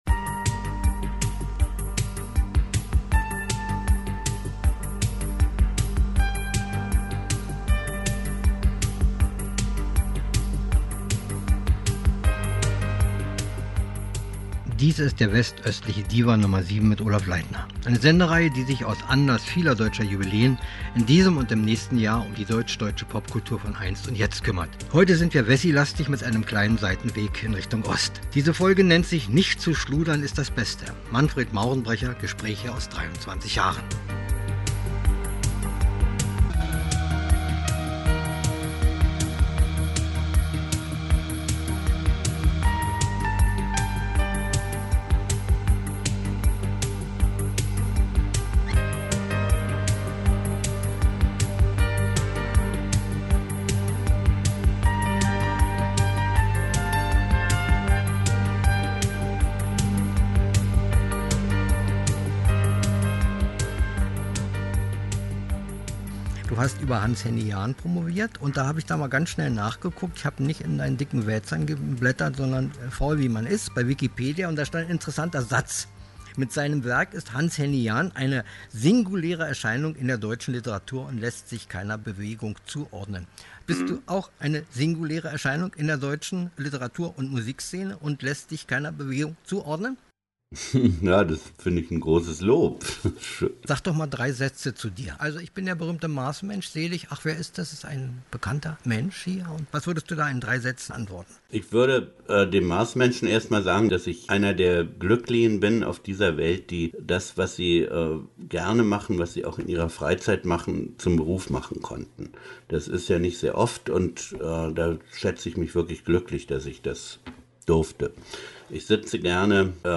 Gespräche mit MM aus 23 Jahren: West-östlicher Diwan